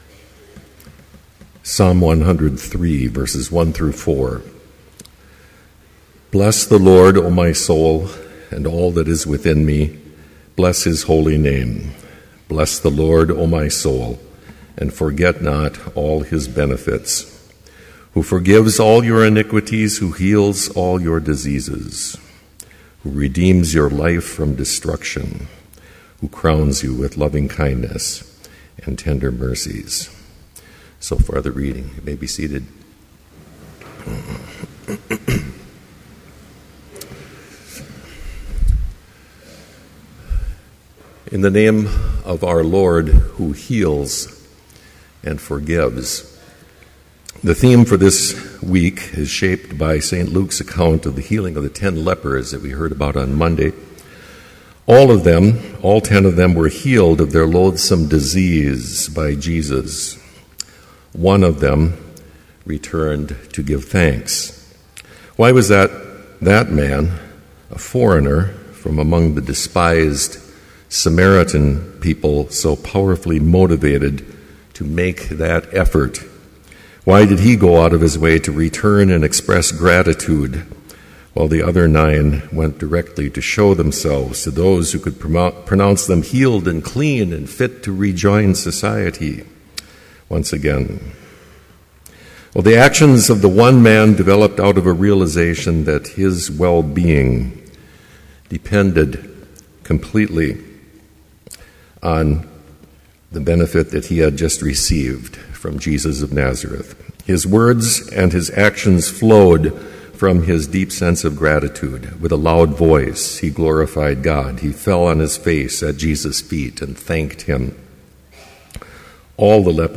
Complete service audio for Chapel - September 12, 2012